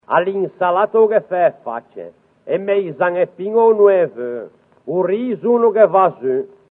Si “ottiene” il dittongo “o-u” /'Ow/, che si trova riportato nella tabella dedicata ai “dittonghi discendenti” contenuta nell'articolo “I dittonghi e gli iati della lingua genovese” presente sul sito come “appendice” alle “Osservazioni sulla grafia di G. Casaccia”.